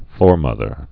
(fôrmŭthər)